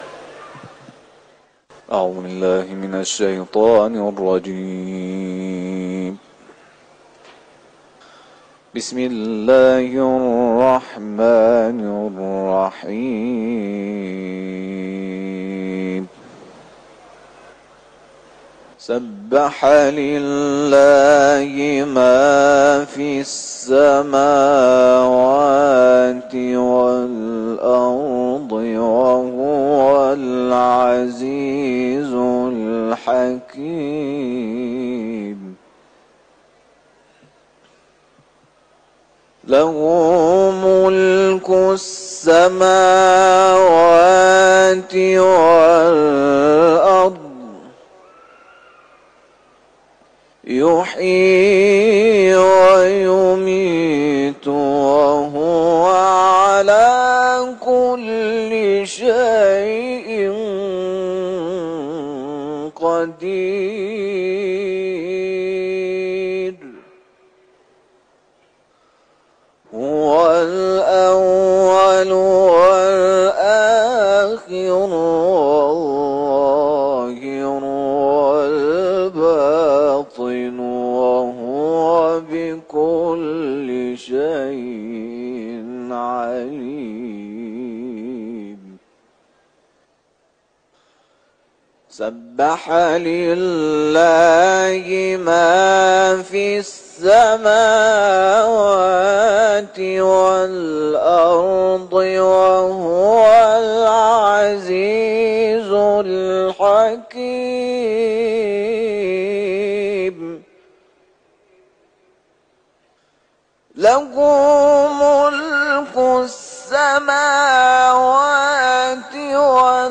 سوره حدید ، حرم مطهر رضوی